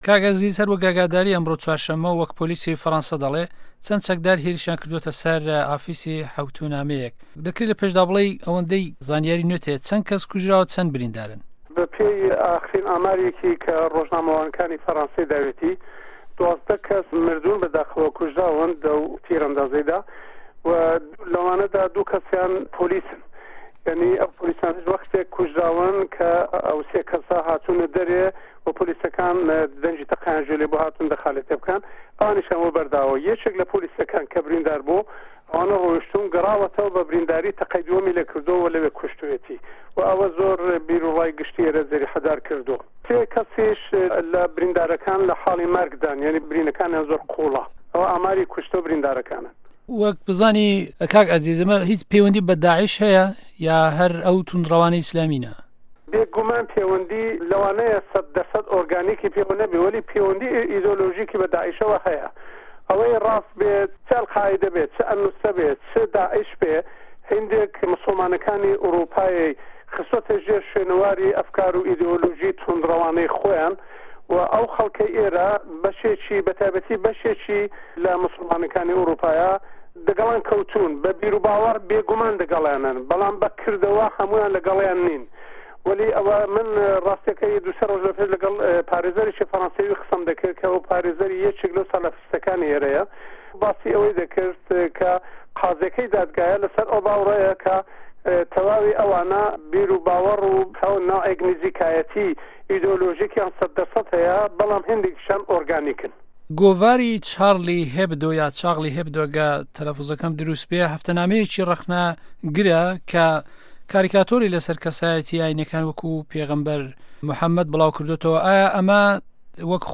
دوو وتووێژ له‌ باره‌ی هێرشه‌که‌ی سه‌ر گۆڤاری شـارلی ئێبدۆی فه‌ڕه‌نسایی